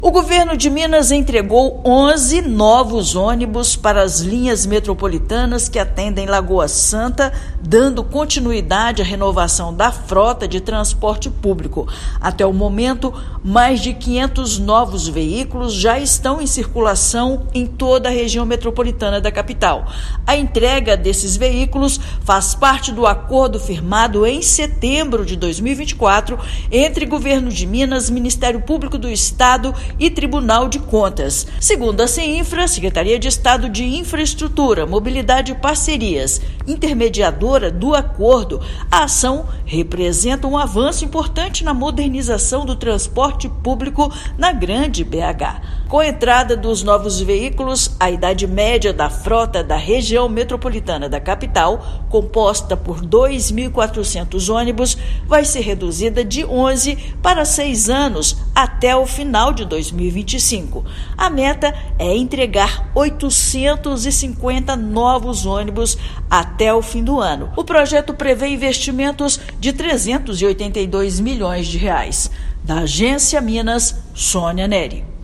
Com os veículos novos, a idade média da frota da RMBH será reduzida de 11 para 6 anos até o final de 2025. Ouça matéria de rádio.